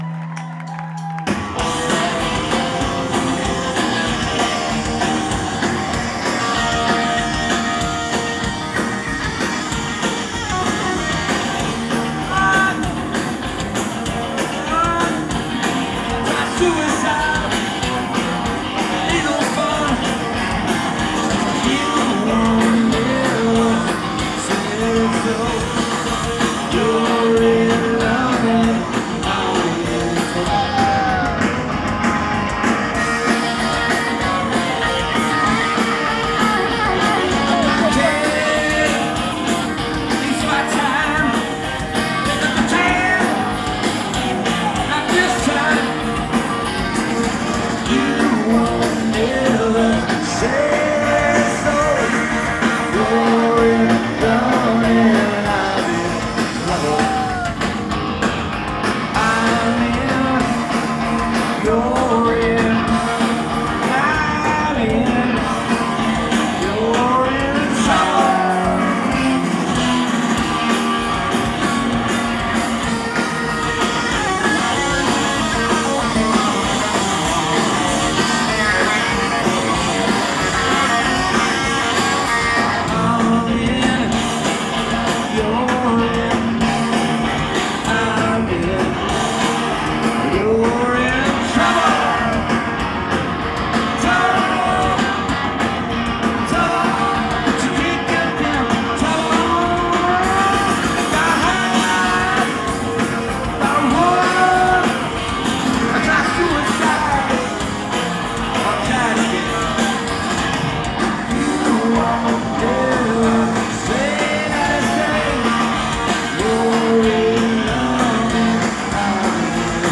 Live at the Sinclair, Cambridge, MA